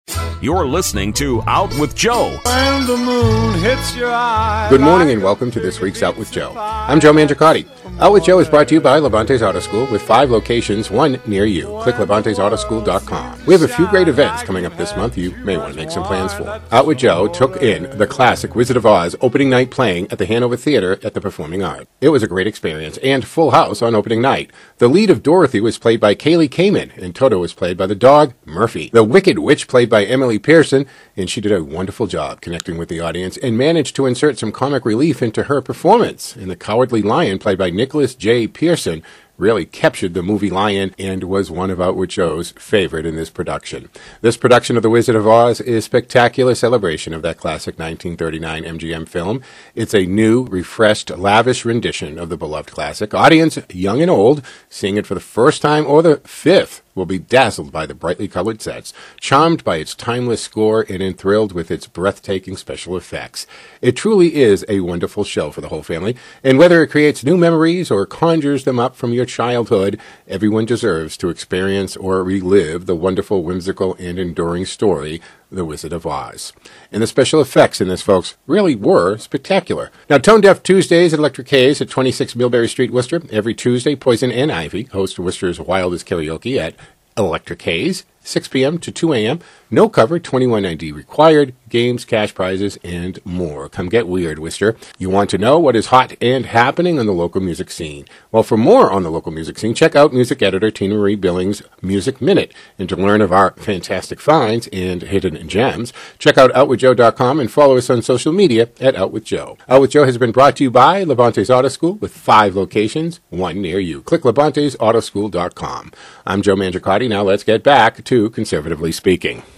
Weekly Vignette on WCRN 830 AM on by